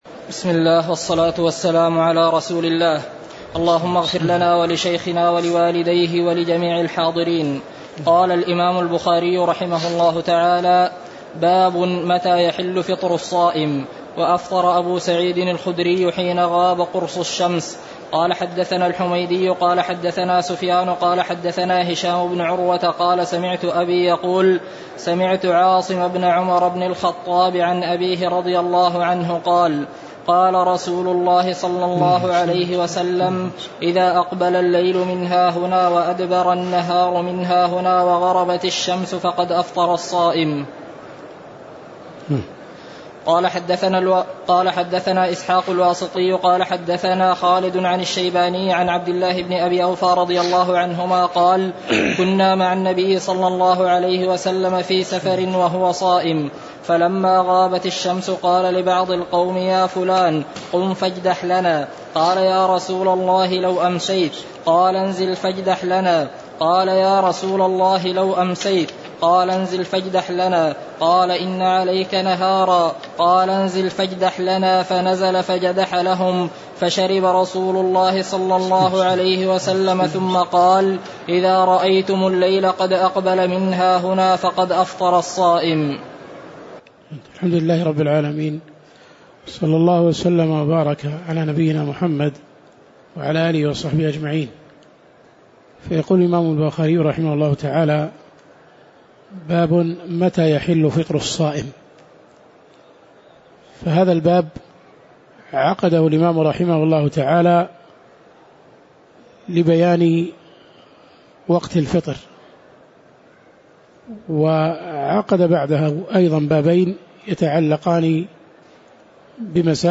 تاريخ النشر ١٢ رمضان ١٤٣٨ هـ المكان: المسجد النبوي الشيخ